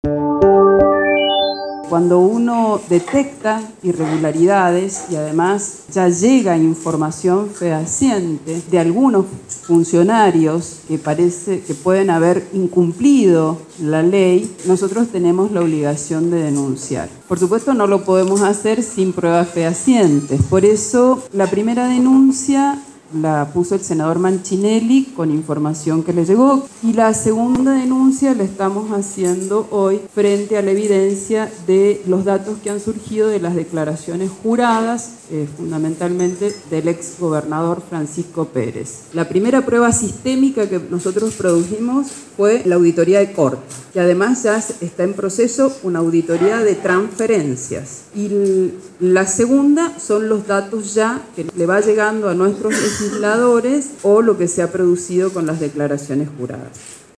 Audio vicegobernadora Laura Montero